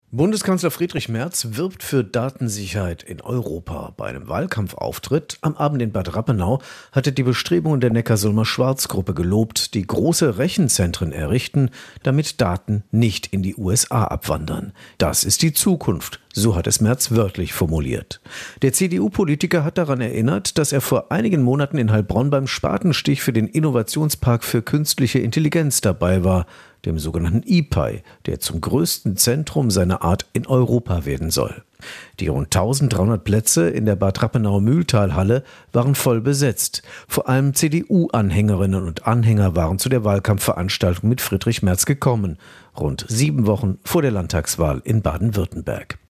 Bei einem Wahlkampfauftritt in Bad Rappenau hat Bundeskanzler Merz die Bedeutung digitaler Souveränität unterstrichen. Außerdem stellte er die telefonische Krankschreibung infrage.